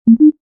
chat_sound.mp3